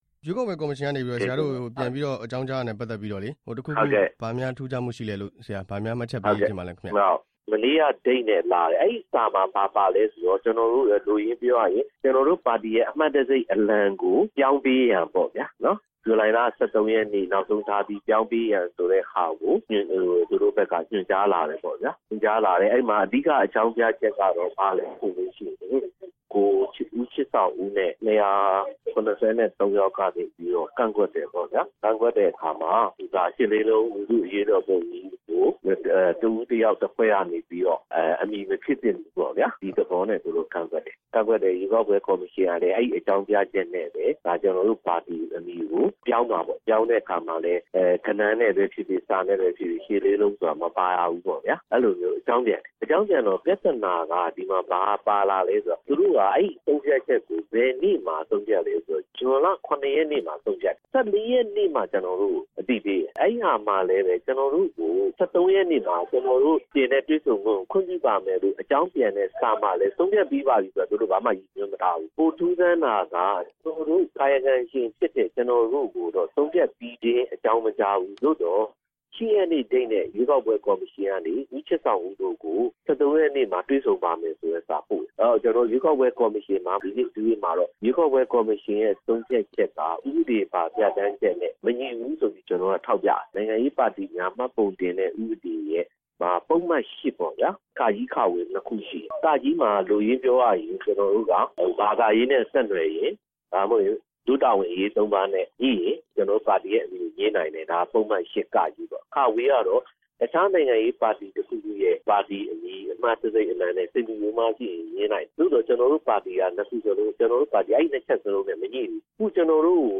ရှစ်လေးလုံးပြည်သူ့ပါတီ တင်ပြချက်ကို ရွေးကောက်ပွဲ ကော်မရှင်က လက်မခံတဲ့ အကြောင်း မေးမြန်းချက်